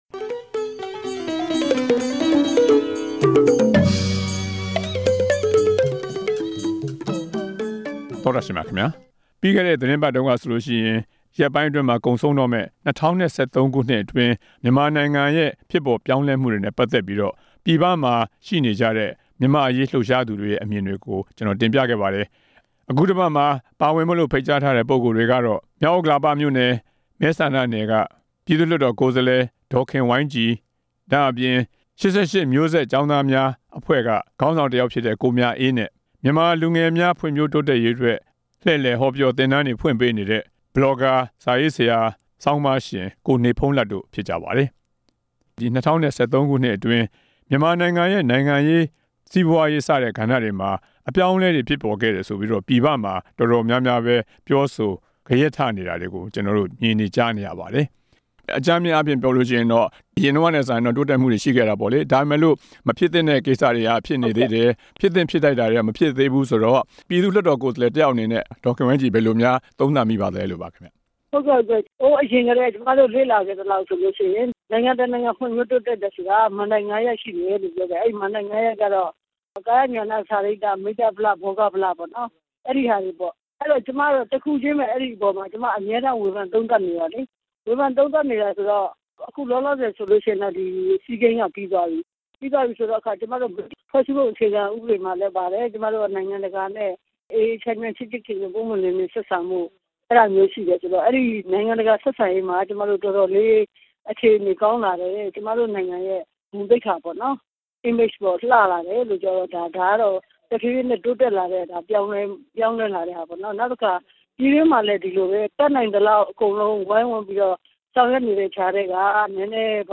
ဆွေးနွေးပွဲစကားဝိုင်း